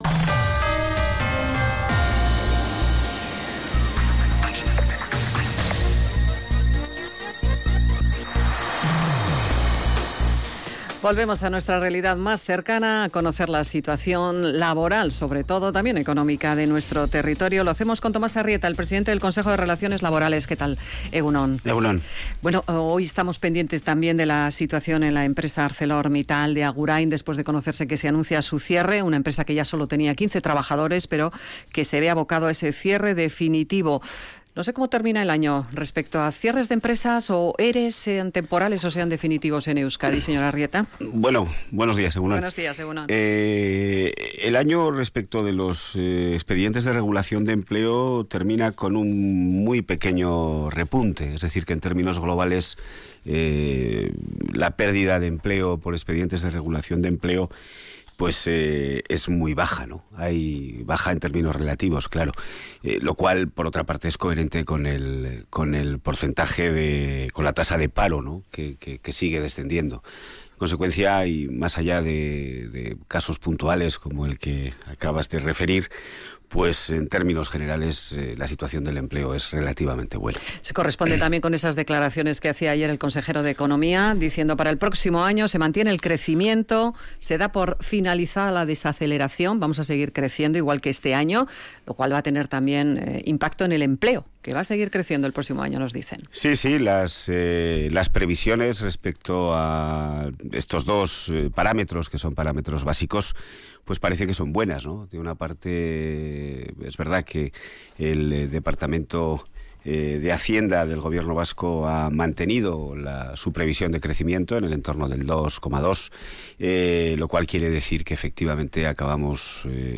El presidente del Consejo de Relaciones Laborales de Euskadi, Tomás Arrieta, ha confirmado en Radio Vitoria un pequeño repunte, nada alarmante, de nuevos expedientes de regulación de empleo.